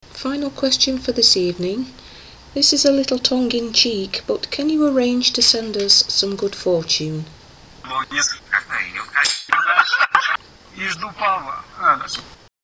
Last week I rounded off my recording session with a tongue in cheek question – I asked if ‘they’ could arrange to send us some good fortune. There were two communicators who replied.
This was recorded by our usual method, Sony B300 and reversed Bulgarian background file – no evp maker or anything of that nature and no editing.